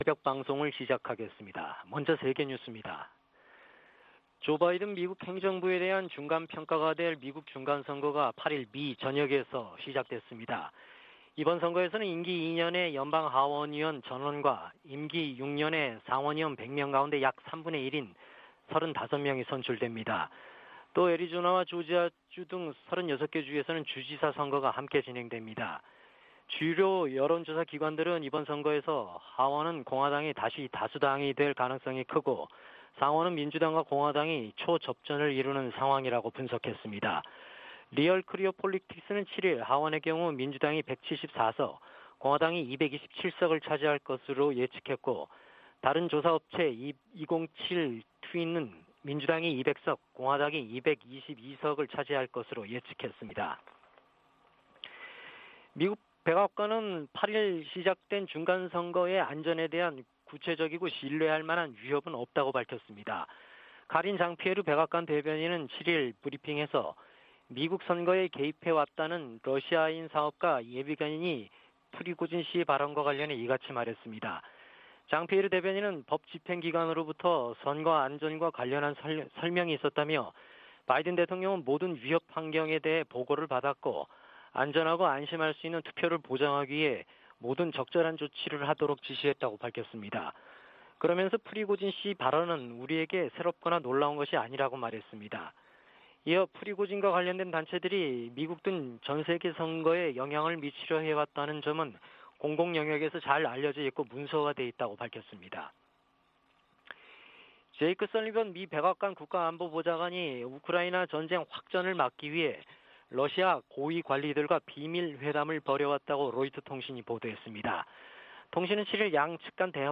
VOA 한국어 '출발 뉴스 쇼', 2022년 11월 9일 방송입니다. 미국에서 임기 2년의 연방 하원의원 435명 전원과 임기 6년의 연방 상원의원 3분의 1을 선출하는 중간선거 투표가 실시되고 있습니다. 미 국무부는 유엔 안보리에서 북한에 대한 제재와 규탄 성명 채택을 막고 있는 중국과 러시아를 정면으로 비판했습니다. 유럽연합은 북한의 잇단 미사일 도발이 전 세계에 심각한 위협이라며 국제사회의 단합된 대응을 촉구했습니다.